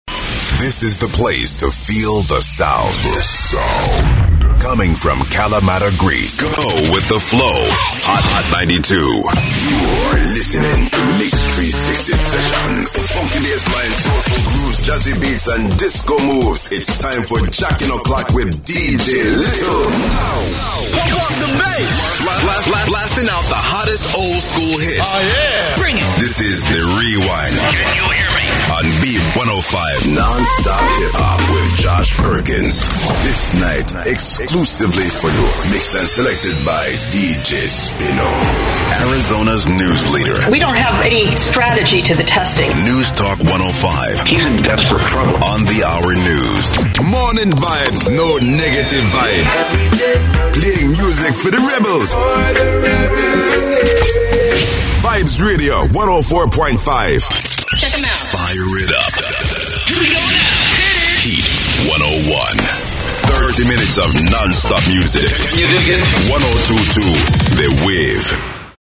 Male
English (American)
Radio Imaging